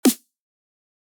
Ableton Liveの標準機能でピッチを変えれるので、5半音と18cent上げます。
ピッチは合ったように聞こえますが、当然ながら早回ししているため全体が短く聞こえます。
特にアタック音が細くなっている事が気になります。